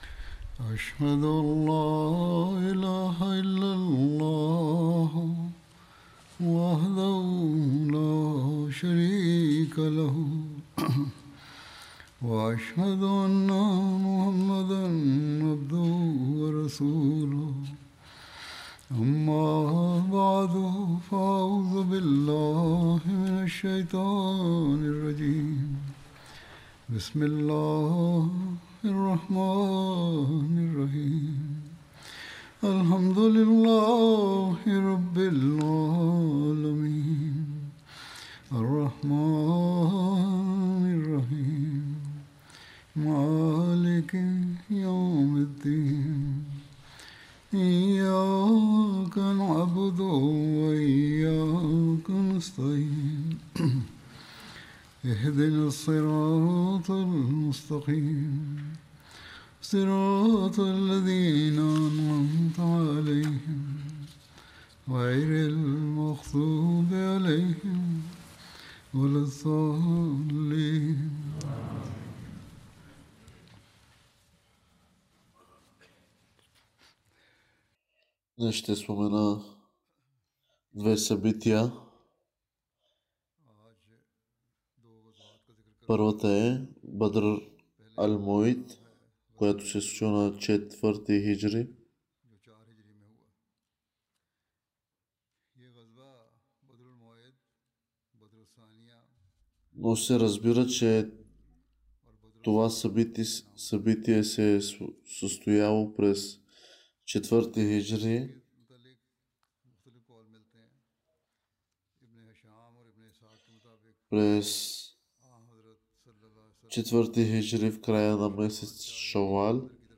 Bulgarian translation of Friday Sermon delivered by Khalifa-tul-Masih on July 5th, 2024 (audio)